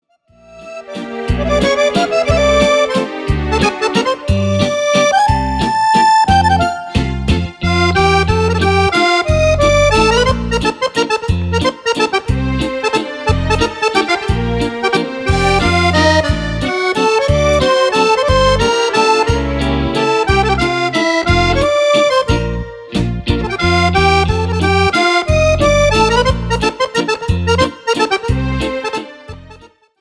VELZER VIENNESE  (3.18)